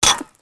auto_hit_tile2.wav